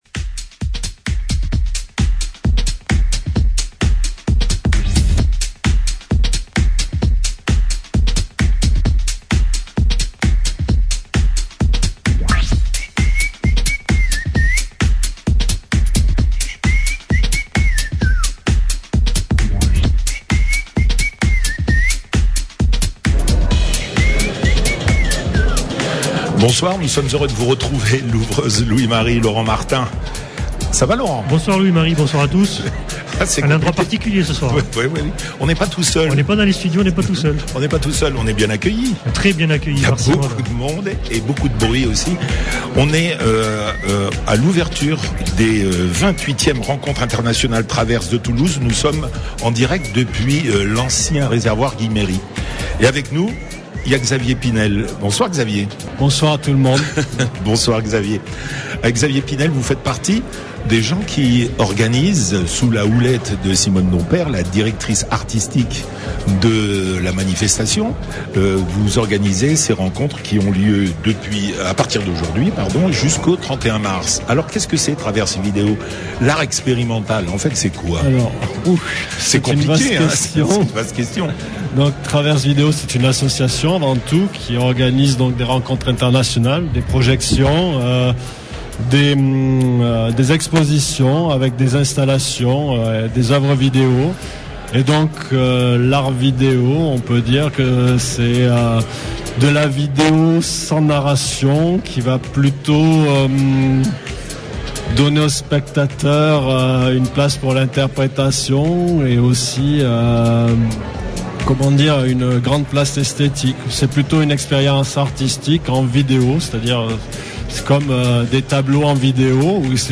L’ouvreuse n’hésite pas à sortir de sa zone de confort, les studios de Présence, pour vous faire découvrir les installations, les photographies et les performances des artistes qui ouvrent les 28èmes rencontres internationales Traverse. À l’Ancien Réservoir de Guilheméry, à Toulouse, la soirée est animée et la déambulation magique. Avec passion et enthousiasme, les talents livrent quelques secrets de leur démarche et de leur inspiration, une impressionnante invitation à la découverte.